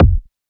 edm-kick-29.wav